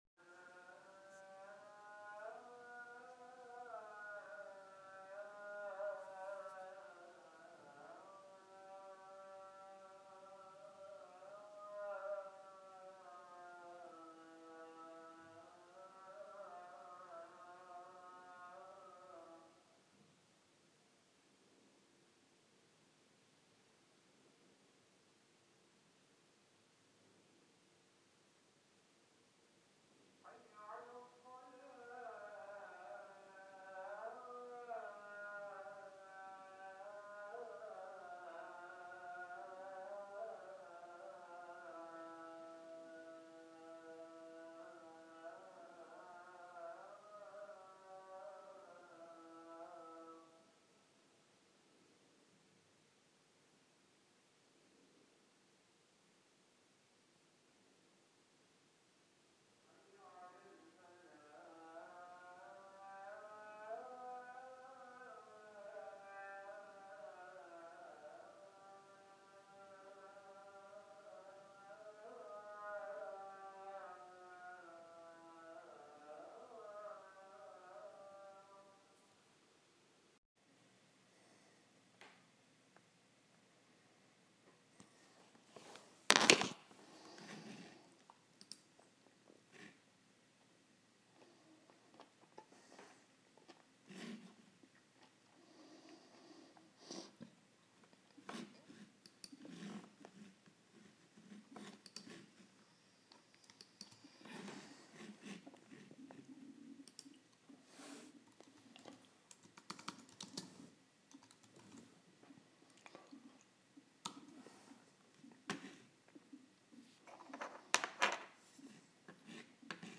мой сегодняшний будильник